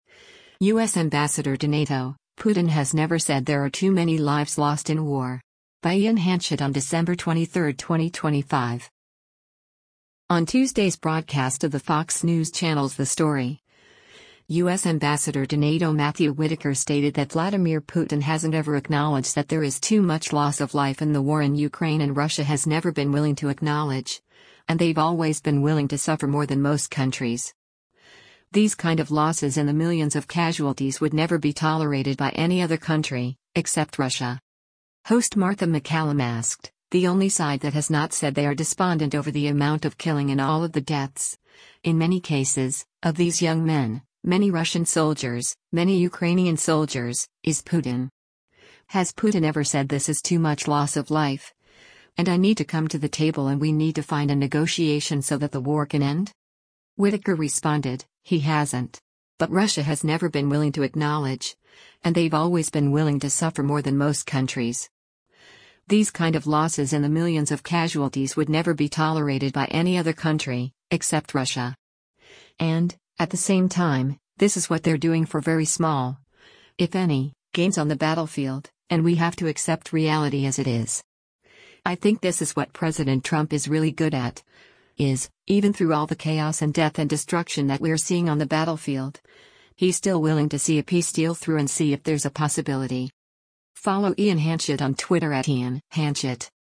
On Tuesday’s broadcast of the Fox News Channel’s “The Story,” U.S. Ambassador to NATO Matthew Whitaker stated that Vladimir Putin hasn’t ever acknowledged that there is too much loss of life in the war in Ukraine and “Russia has never been willing to acknowledge, and they’ve always been willing to suffer more than most countries. These kind of losses in the millions of casualties would never be tolerated by any other country, except Russia.”